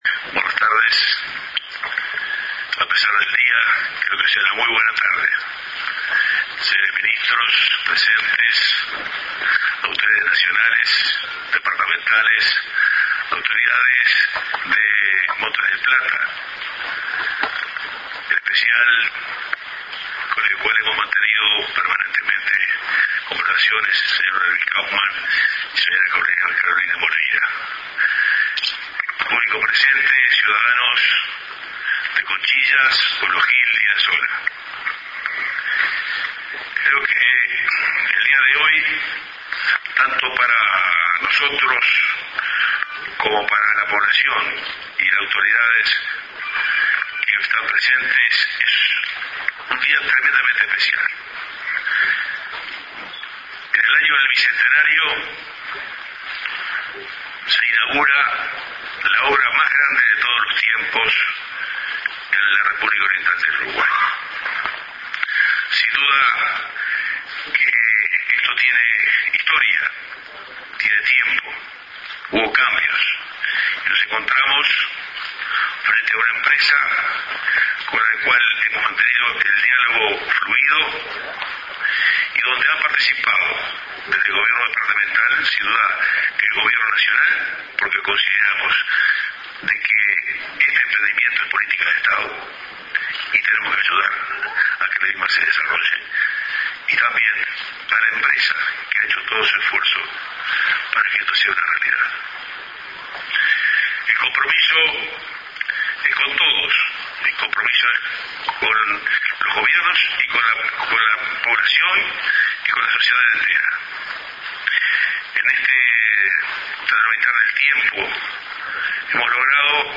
El acto inaugural de la zona franca de Punta Pereira